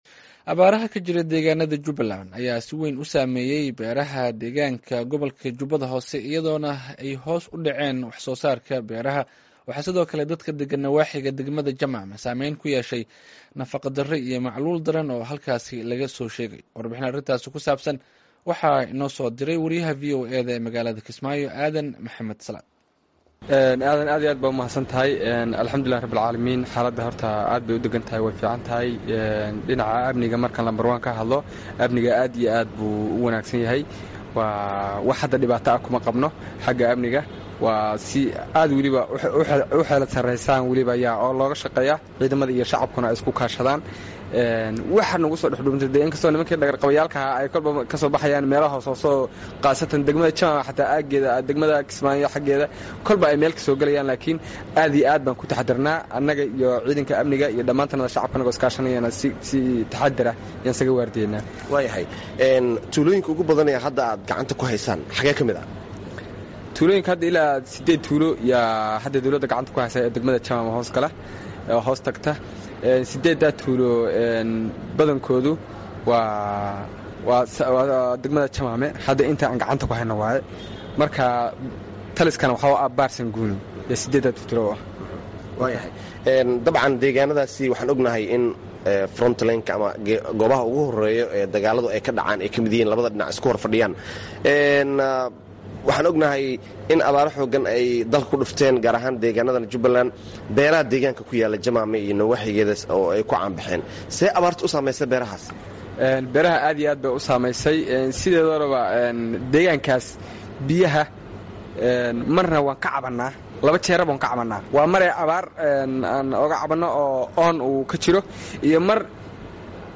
Wareysi: Waxyeellada abaaraha ka jira deegaannada Jubaland